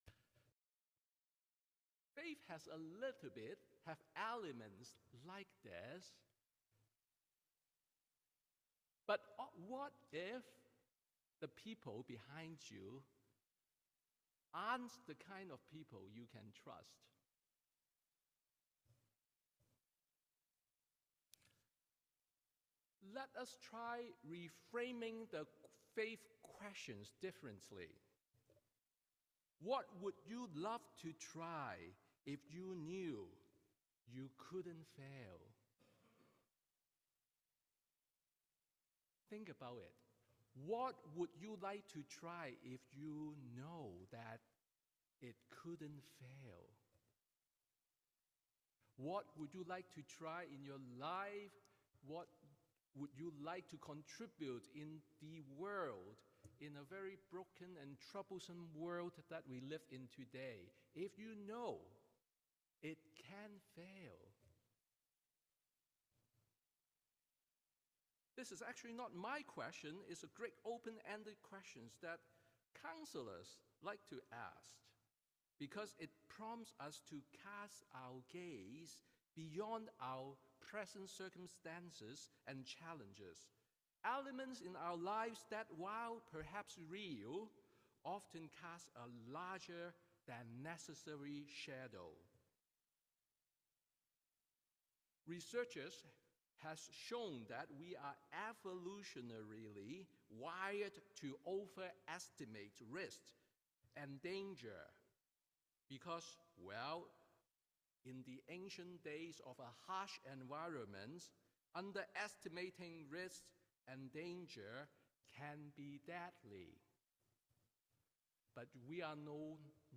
Sermon on Pride Sunday / Ninth Sunday after Pentecost